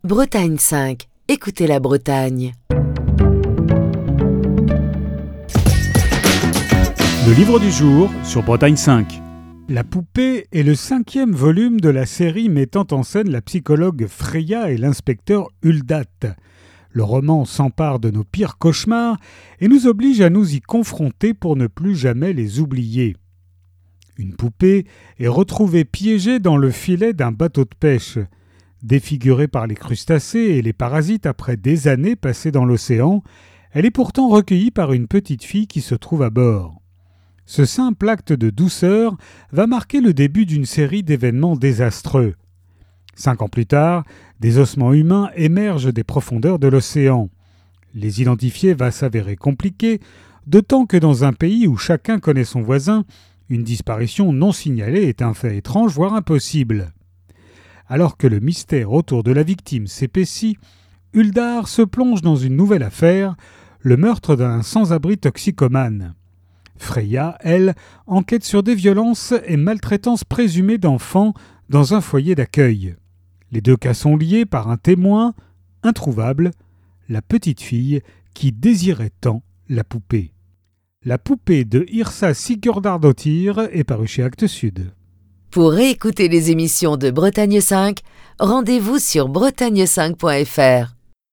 Chronique du 8 septembre 2023.